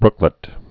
(brklĭt)